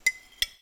Babushka / audio / sfx / Kitchen / SFX_Spoon_01.wav
SFX_Spoon_01.wav